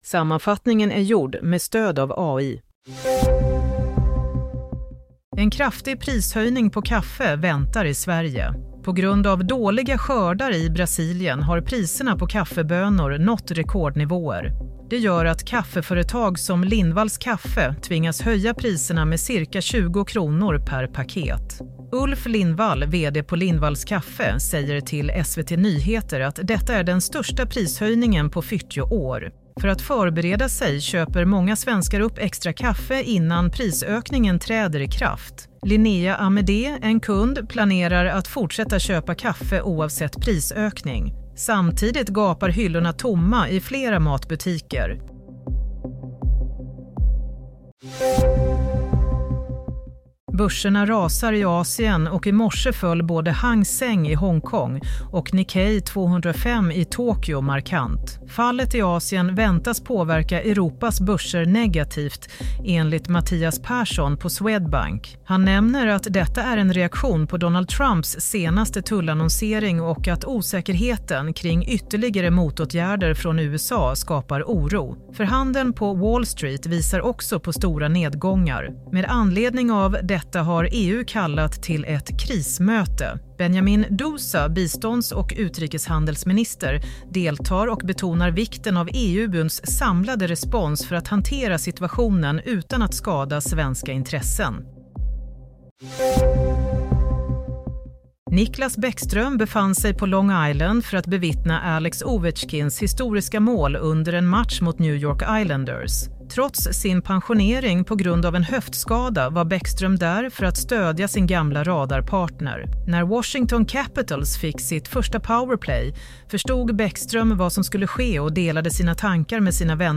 Play - Nyhetsssammanfattning 7 april
Play Nyhetsssammanfattning 7 april Denna sammanfattning är skapad med hjälp av AI. Börserna rasar – krismöte i EU Tomt på kaffehyllorna inför chockhöjning Ovetjkin hyllade Bäckström: ”Började nästan gråta” Broadcast on: 07 Apr 2025 Summary Denna sammanfattning är skapad med hjälp av AI.